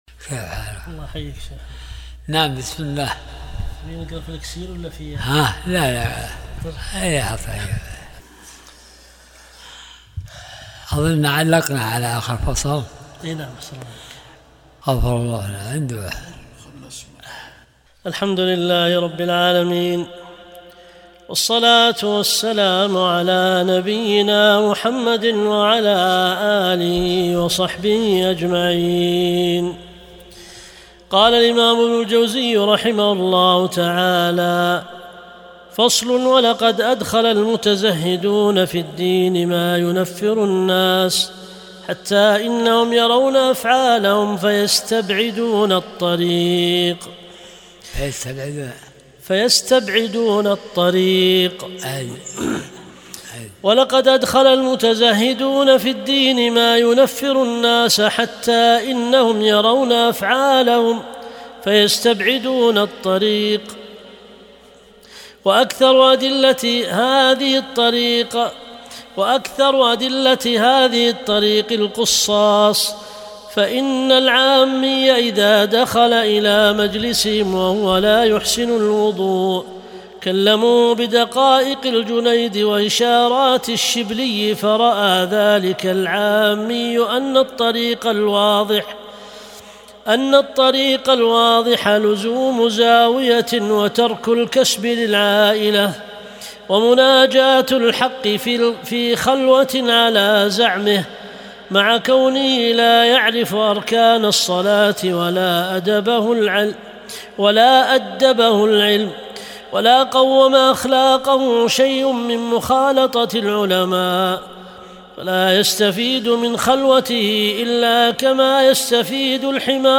درس الأربعاء 70